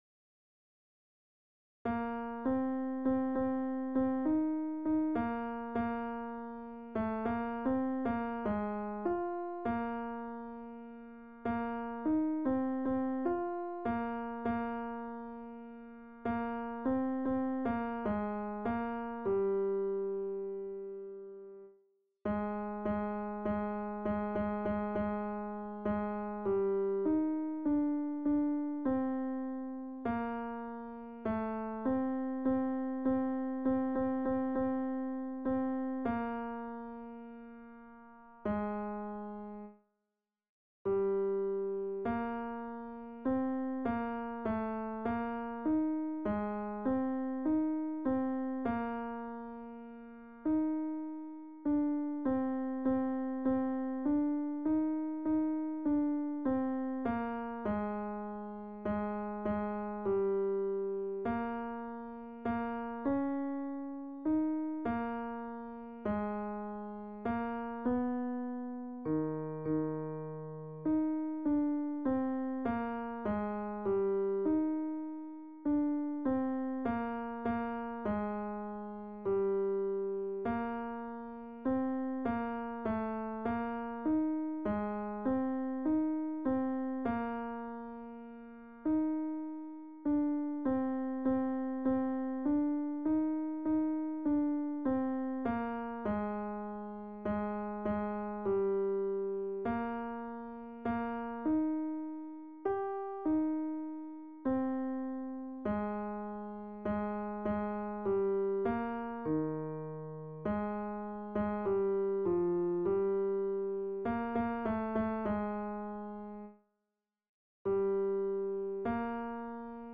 Noël_Blanc-Ténor.mp3